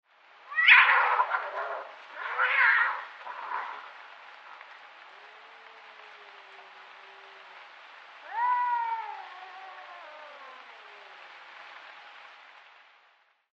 На этой странице собраны звуки драки между кошкой и собакой – от яростного шипения и лая до забавного визга и рычания.
Крик кошки и лай собаки в уличной драке